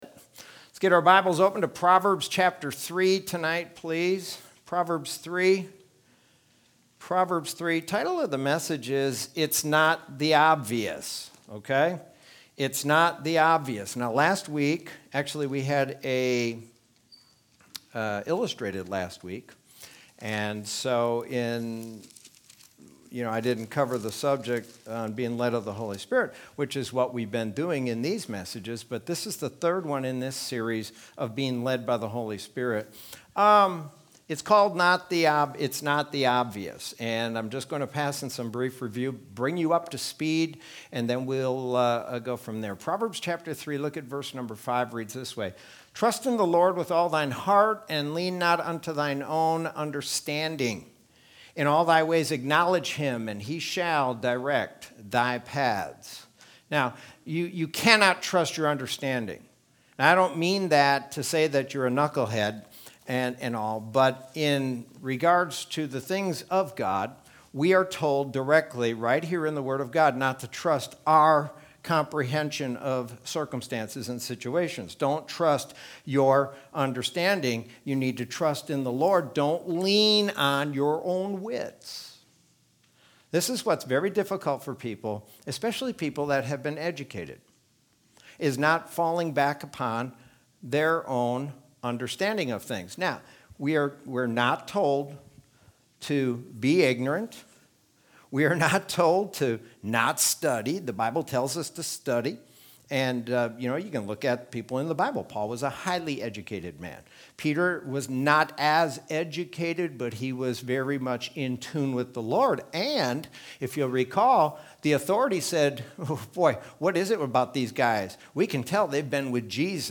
Sermon from Wednesday, October 7th, 2020.